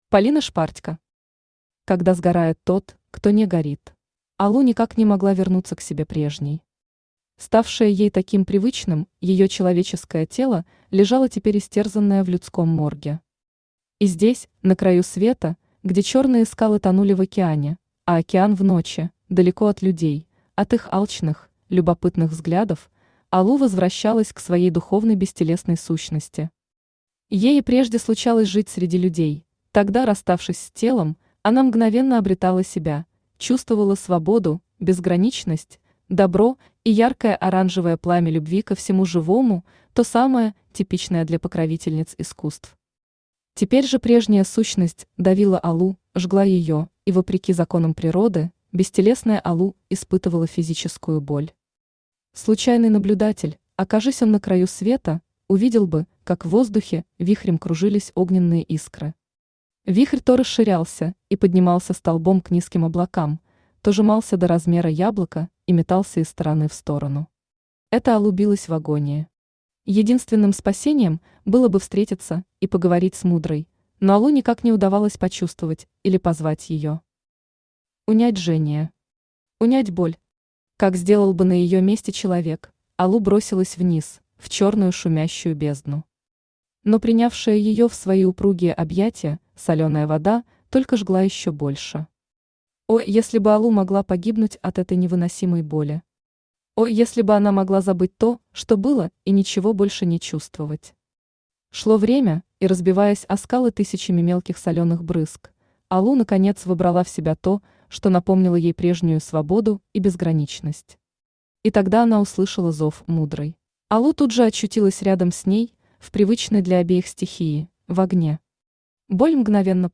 Аудиокнига Когда сгорает тот, кто не горит | Библиотека аудиокниг
Aудиокнига Когда сгорает тот, кто не горит Автор Полина Викторовна Шпартько Читает аудиокнигу Авточтец ЛитРес.